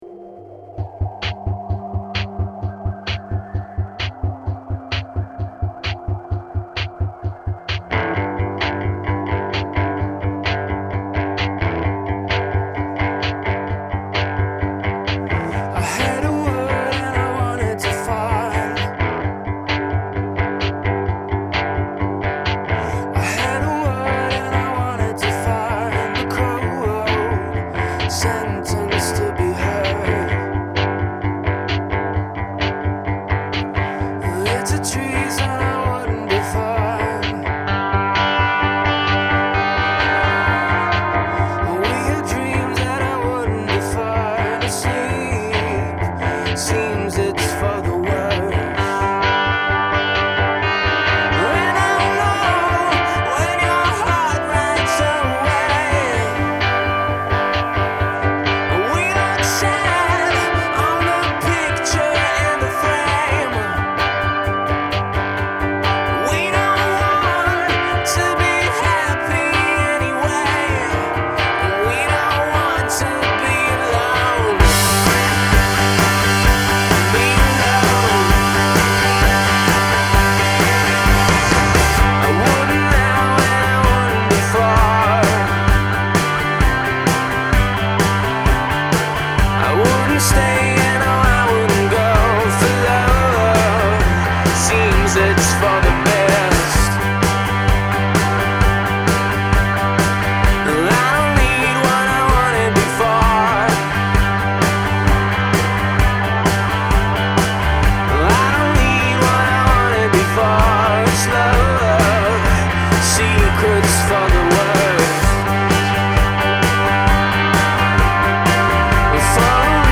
Ballader: